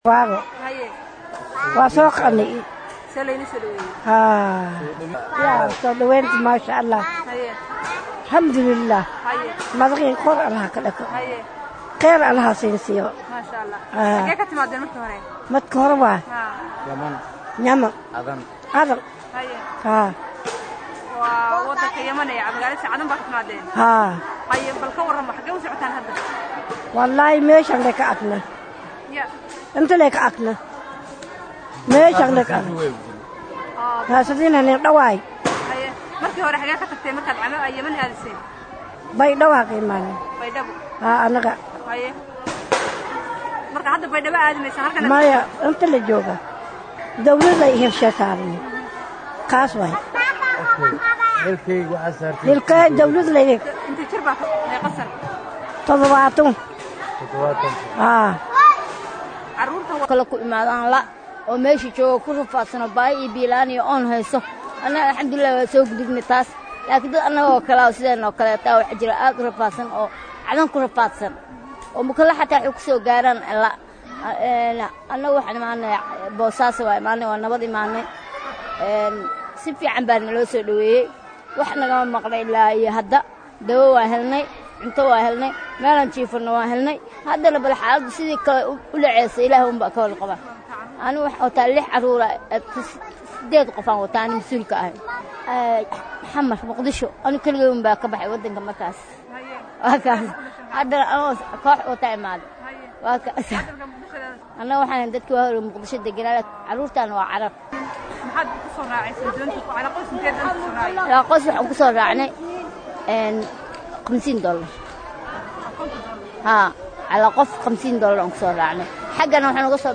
Halkan Ka Dhageyso Codka Qoxooti Ka Soojeeda KGS Oo Soo Gaarey Bosaaso